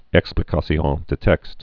(ĕk-splē-kä-syôɴ də tĕkst)